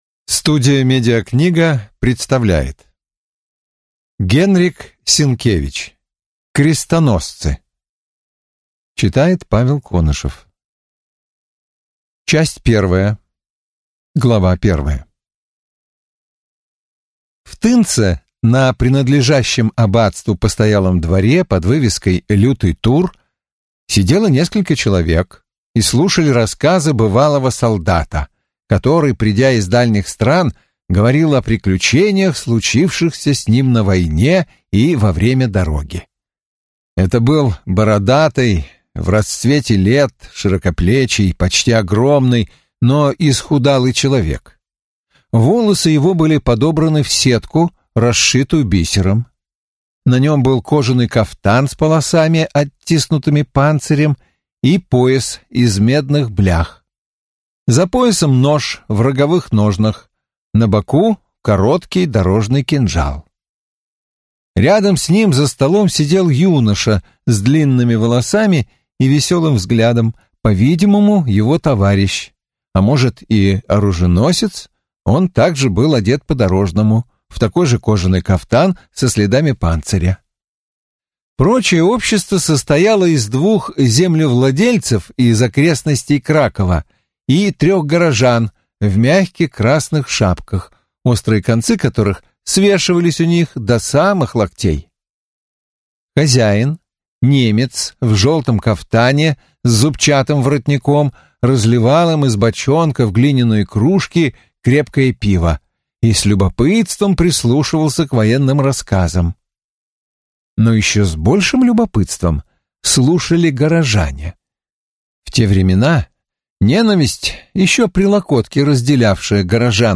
Студия «МедиаКнига» представляет культовую эпическую аудиокнигу по роману знаменитого польского писателя, мастера исторического романа, лауреата Нобелевской премии по литературе 1905 года – Генрика С...